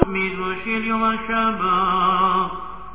In general, the chazzan recites aloud only the first sentence of each mizmor.
Most of the recordings are taken from zemirot Shabbat.